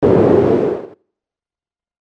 アタック 007 impact 2
コーグォー